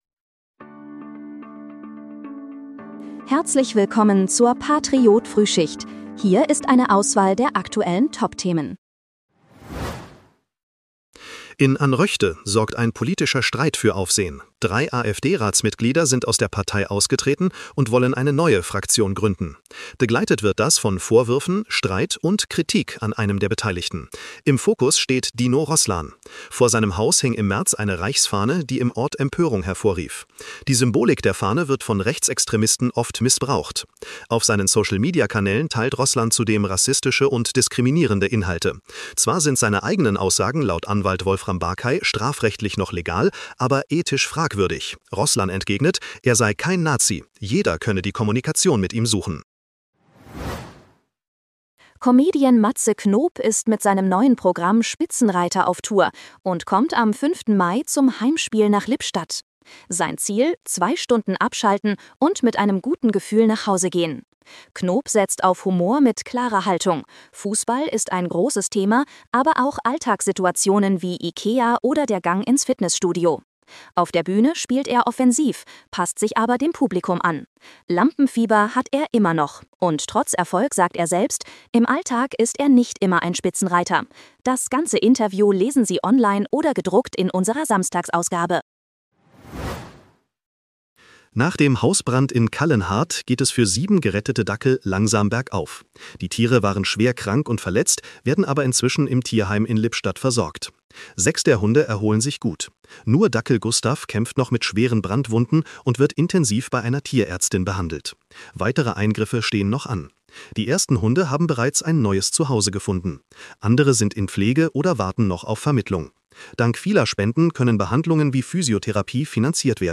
Dein morgendliches News-Update